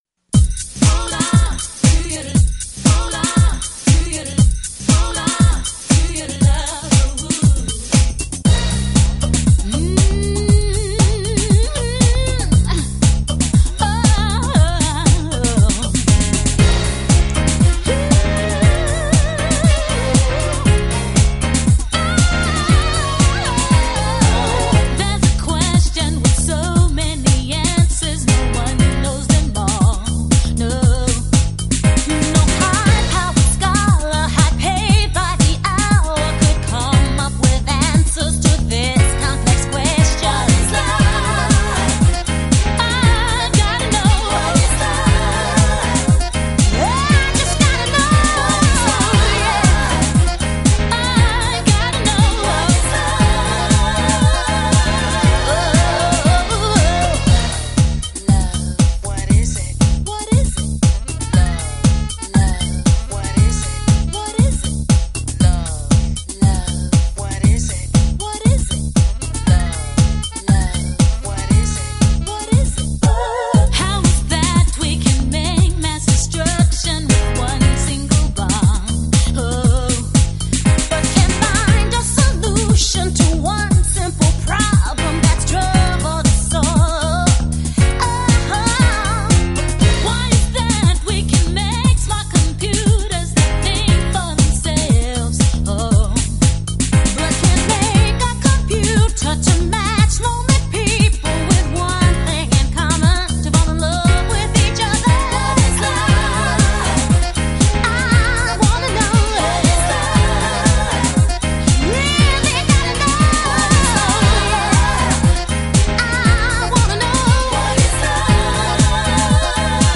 R&B 女子团体
嗓音宽达4个音域